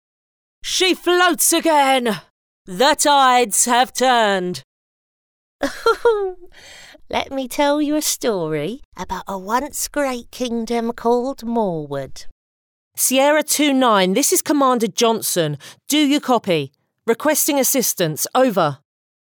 Natural, Llamativo, Accesible, Versátil, Cálida